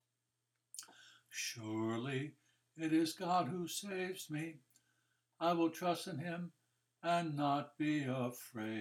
Isaiah 1 – Easy chants and committed language for the Daily Office